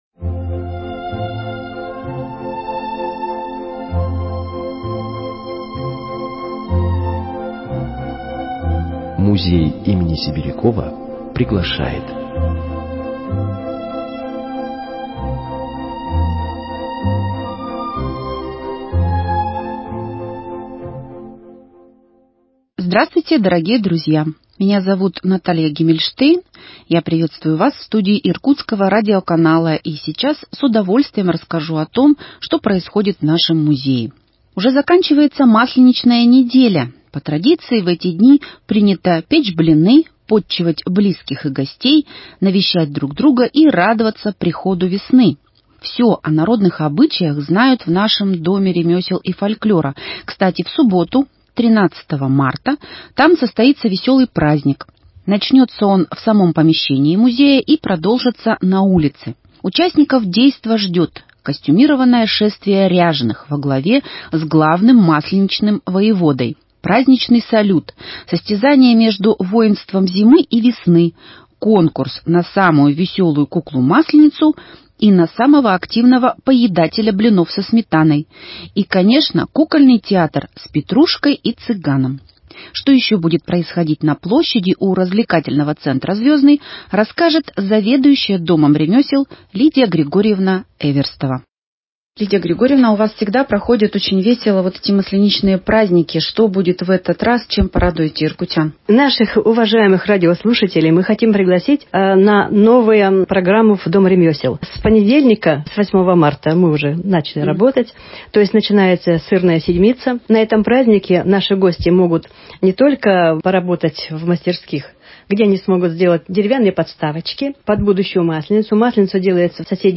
Еженедельная передача, выходящая по пятницам.